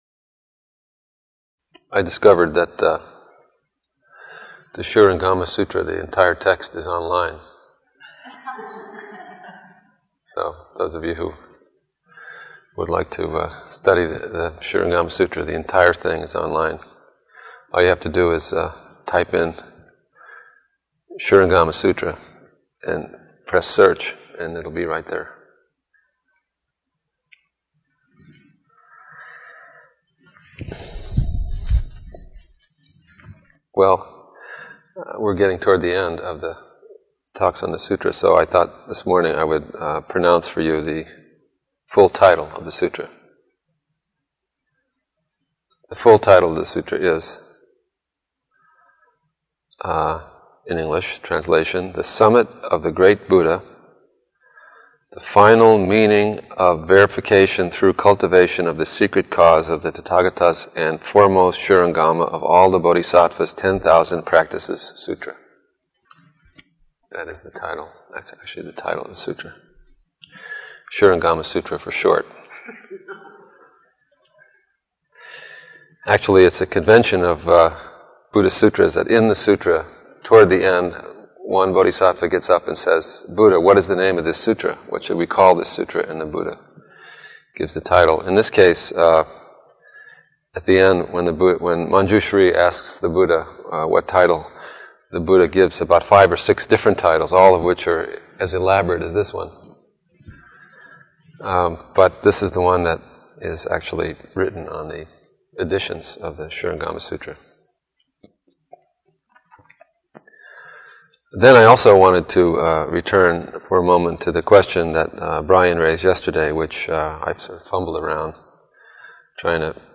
08/01/2001 Location: Samish Island In Topics